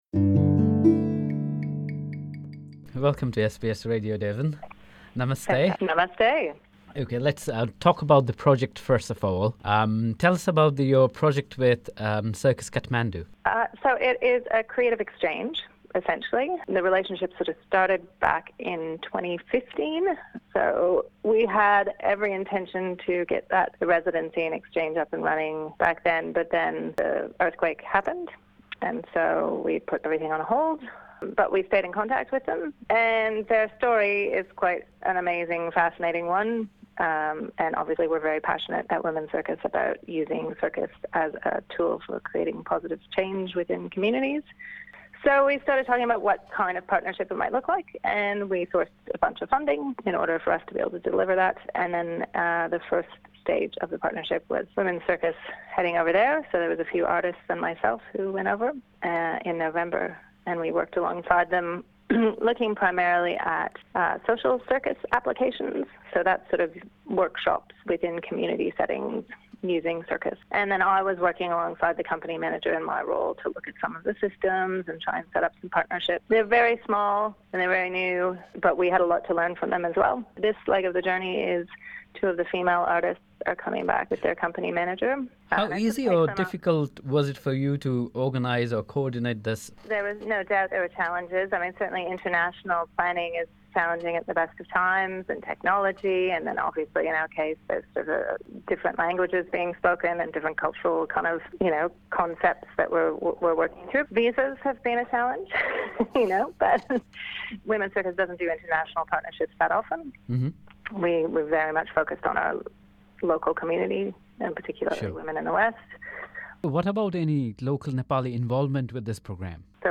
ck_dt_interview_ap.mp3